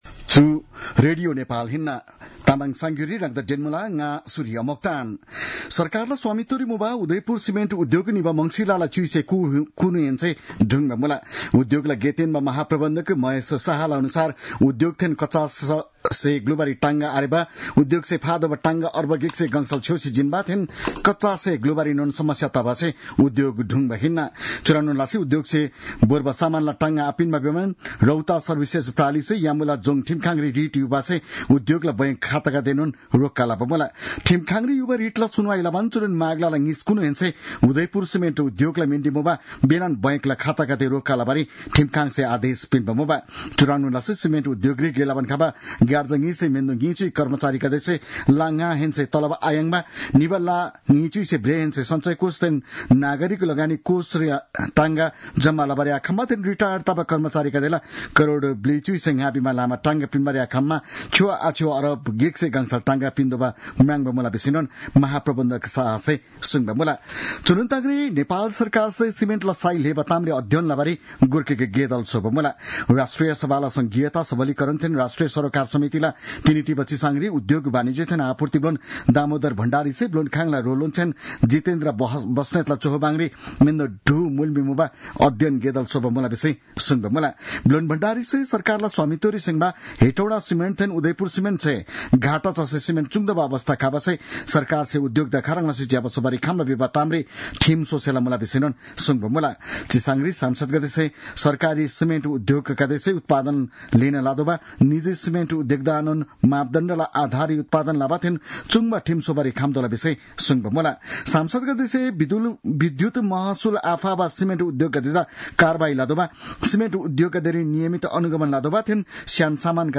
तामाङ भाषाको समाचार : ३० माघ , २०८१